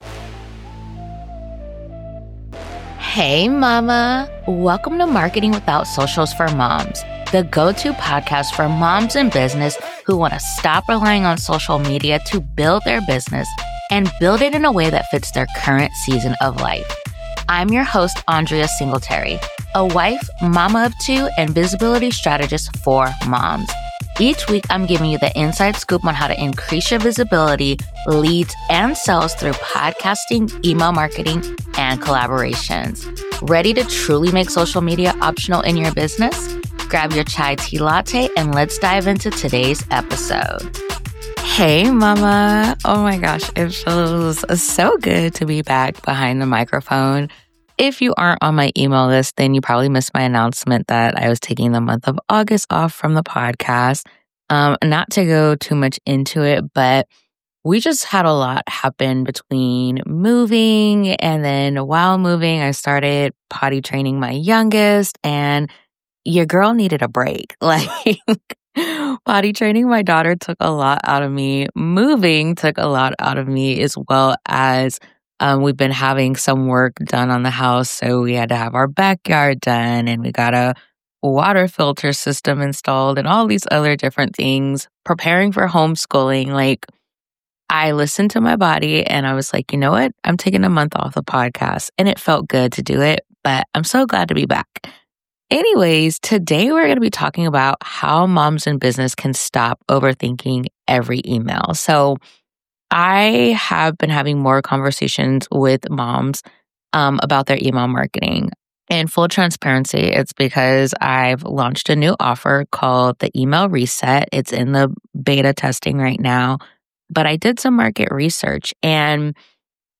You’re getting email replies and have high open rates, but none of that is translating to sales. If that’s been your reality, then today’s interview is for you.